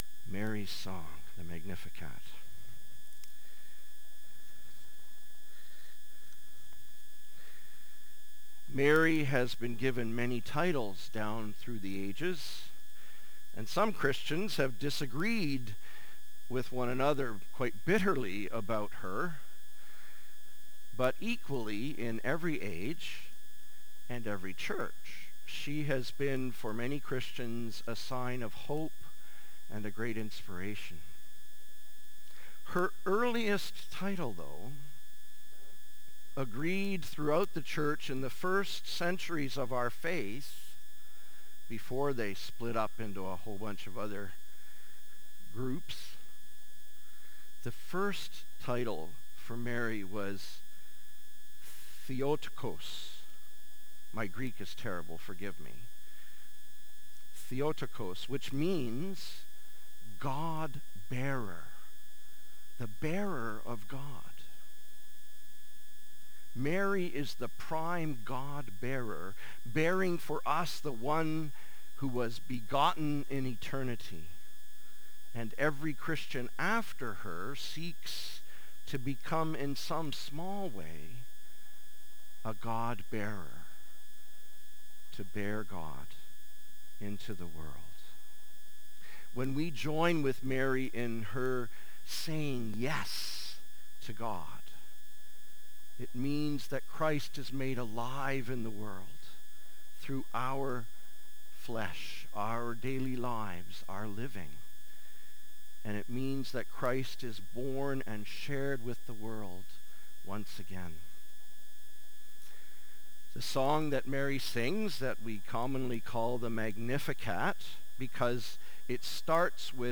Sermons | Gilmore Park United Church